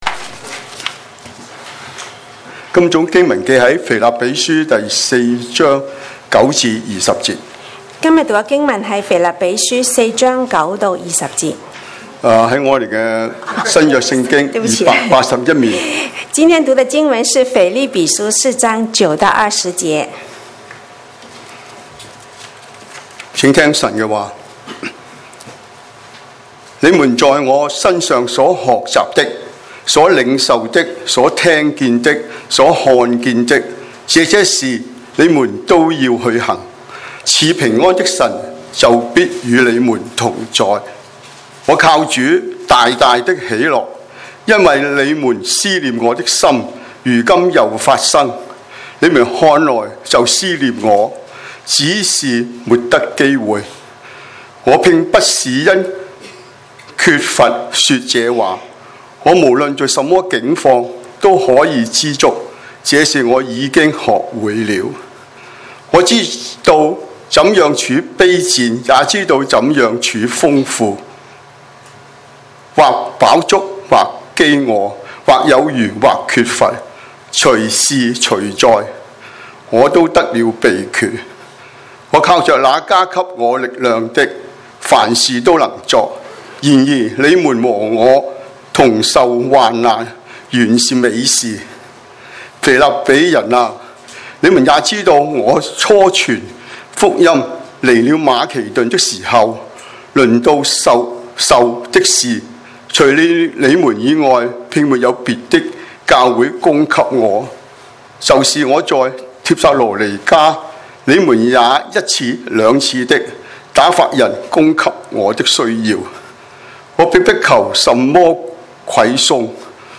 2020 講道錄音